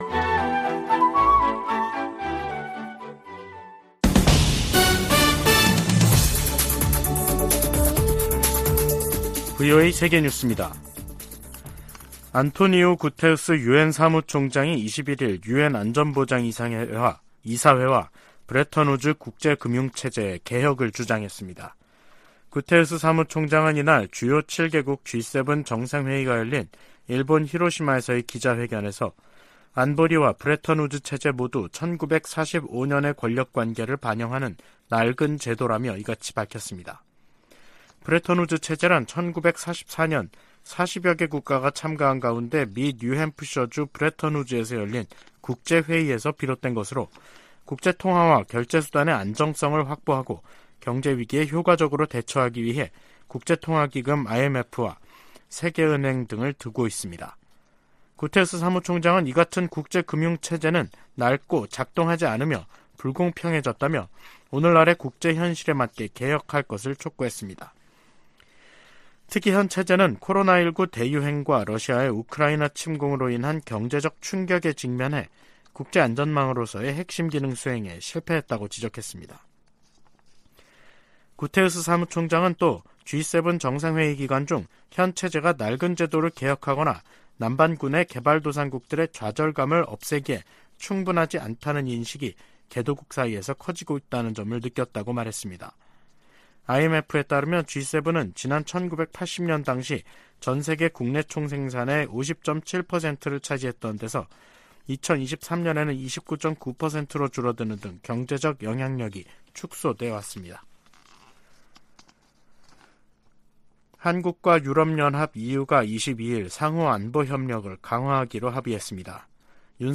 VOA 한국어 간판 뉴스 프로그램 '뉴스 투데이', 2023년 5월 22일 3부 방송입니다. 조 바이든 미국 대통령과 윤석열 한국 대통령, 기시다 후미오 일본 총리가 히로시마 회담에서 새로운 공조에 합의했습니다. 주요7개국(G7) 정상들은 히로시마 공동성명에서 북한의 계속되는 핵과 탄도미사일 위협을 규탄했습니다. 미일 외교장관들이 북한의 완전한 비핵화를 위한 미한일 3각 공조의 중요성을 강조했습니다.